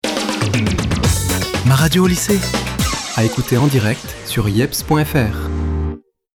Jingle Court